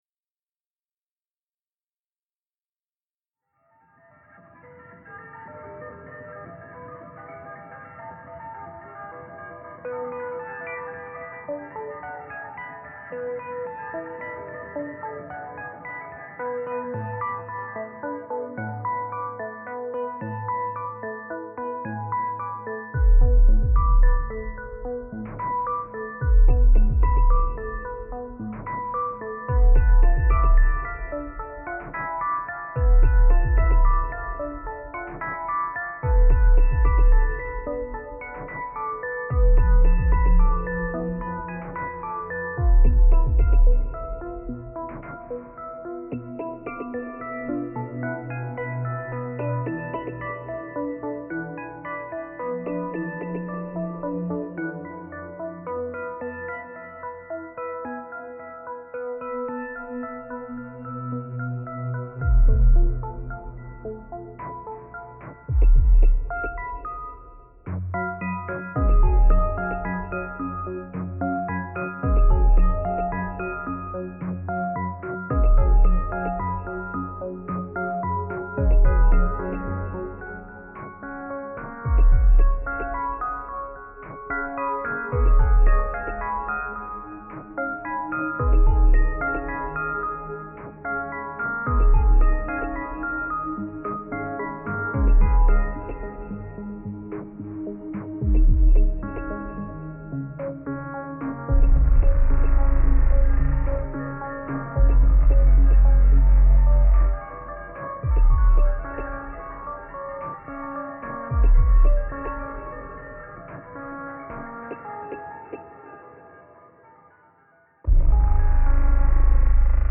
village sounds reimagined